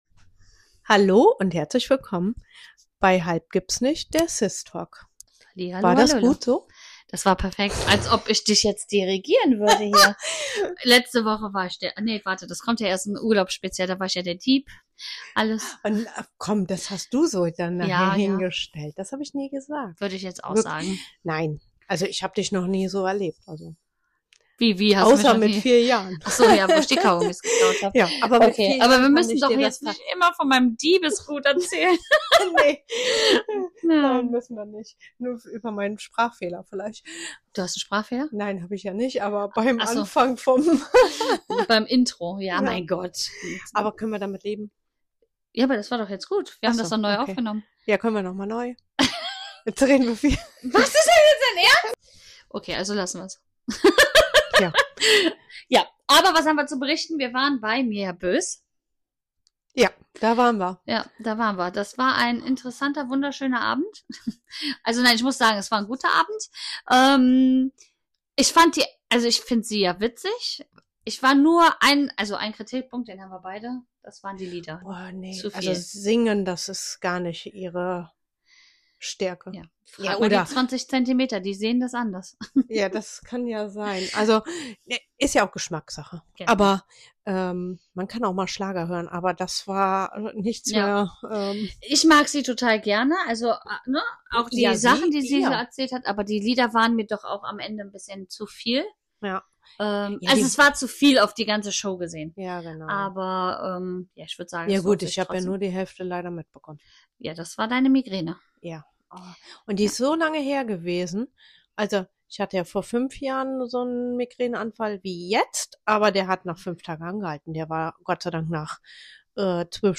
Diese Woche nehmen die zwei Schwestern euch mit auf ihre ganz persönliche Sommerreise – im Kopf und in echt.